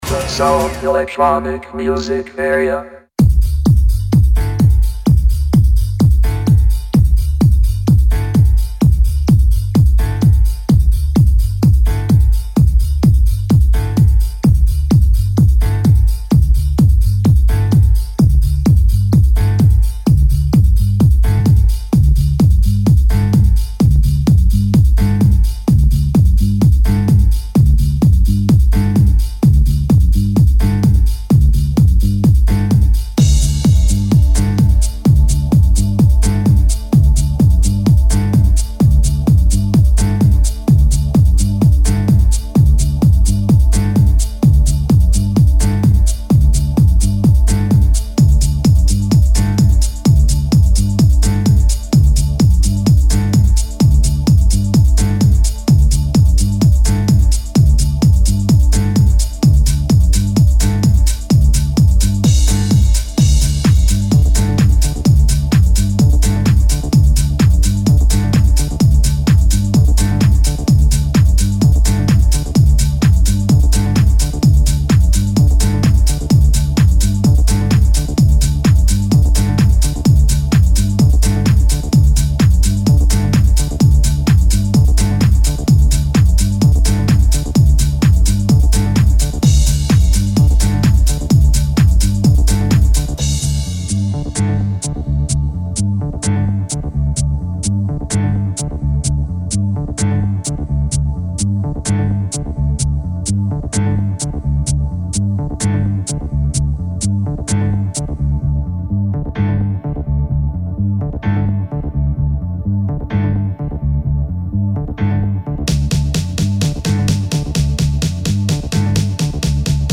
DJset
FULL AUDIO HQ REMASTERED